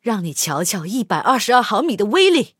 SU-122A开火语音2.OGG